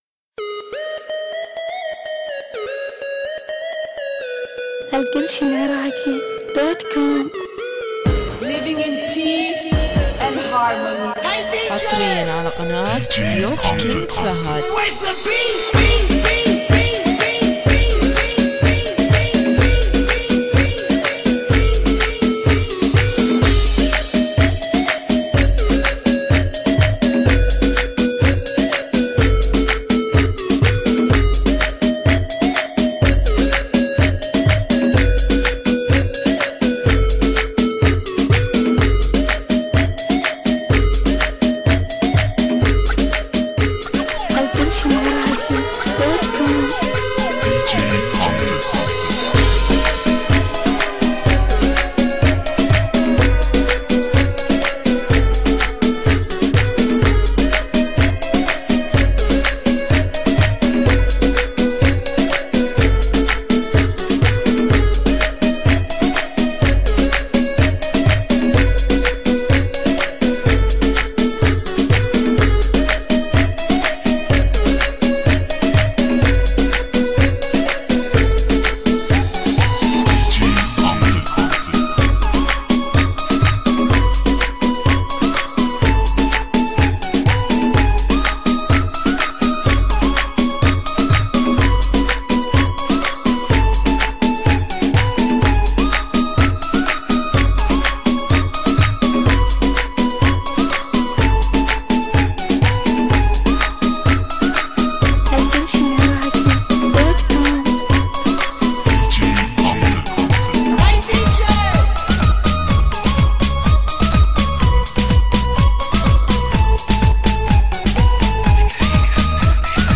ديجي هجوله اجنبي